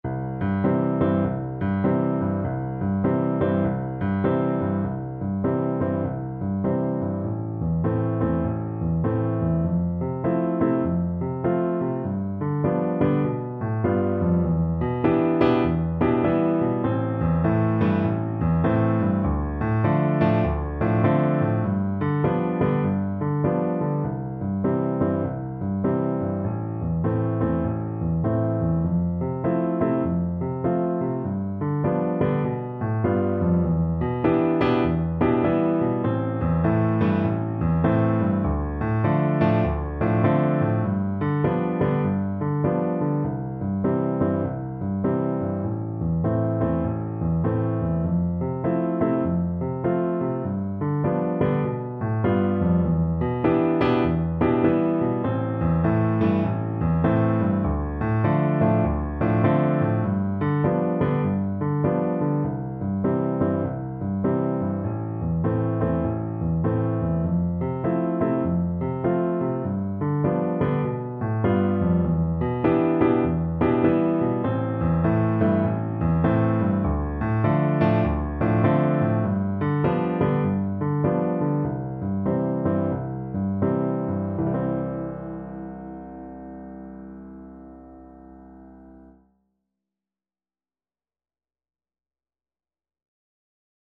C major (Sounding Pitch) (View more C major Music for Bassoon )
With swing =c.100